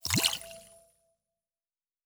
Fantasy Interface Sounds
Potion and Alchemy 06.wav